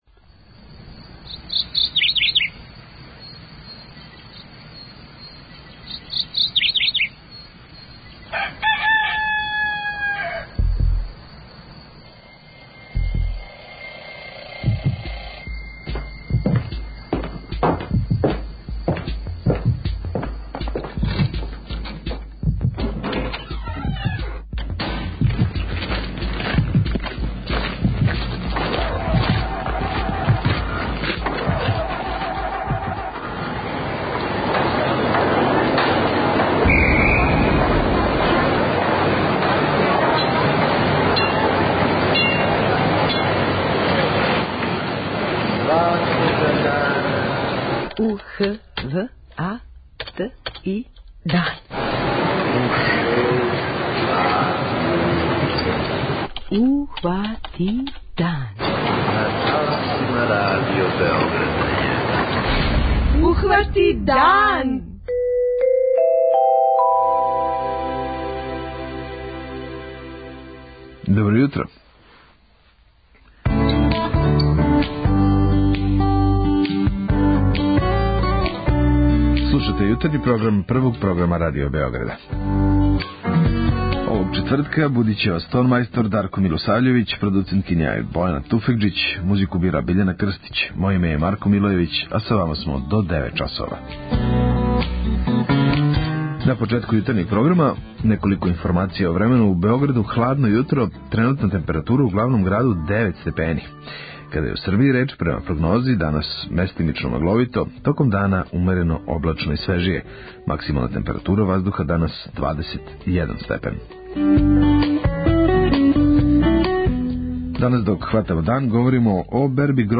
преузми : 21.56 MB Ухвати дан Autor: Група аутора Јутарњи програм Радио Београда 1!